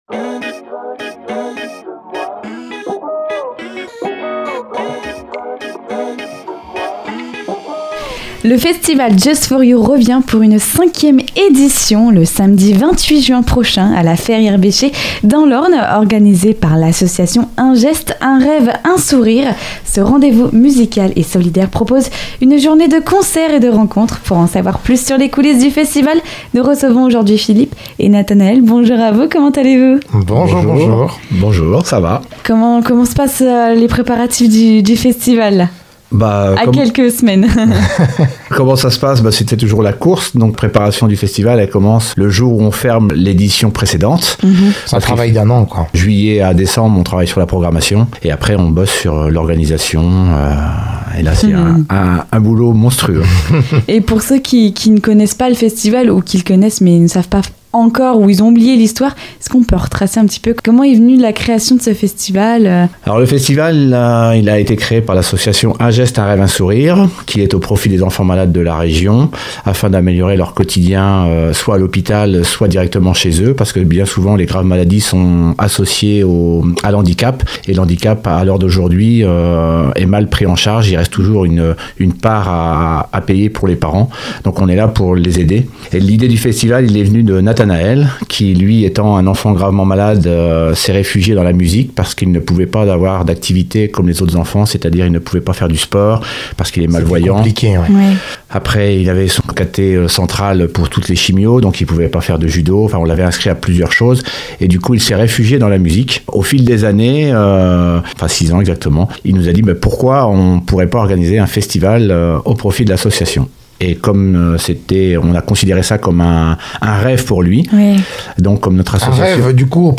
Pour en savoir plus sur cet rencontre n'hésitez pas à écouter jusqu'au bout l'interview, et pourquoi pas vous aussi, faire partie des festivaliers ! culture local festival